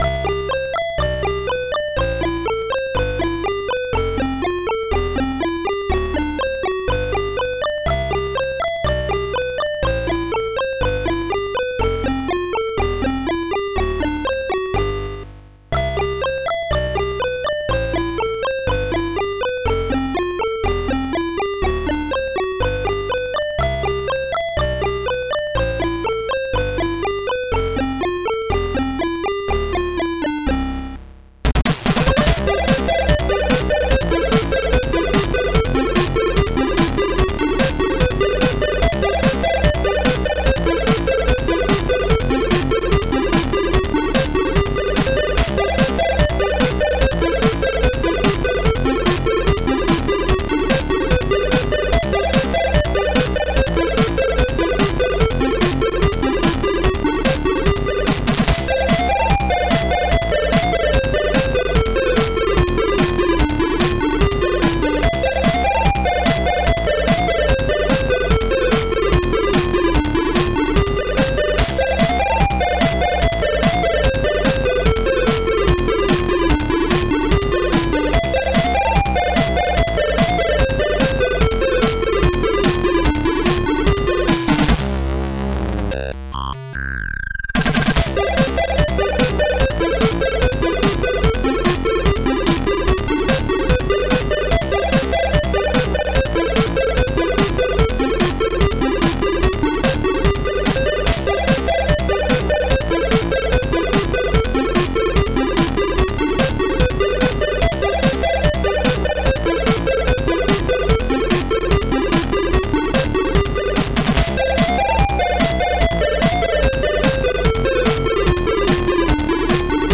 ZX Spectrum + AY
• Sound chip AY-3-8912 / YM2149
Realtime 8Bit Music Cover